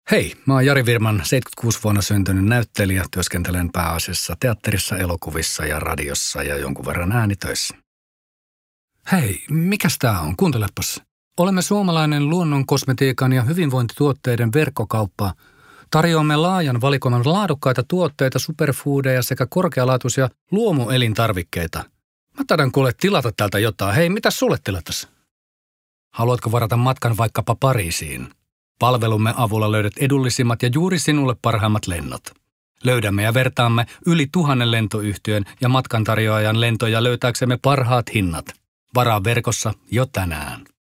Voice color: neutral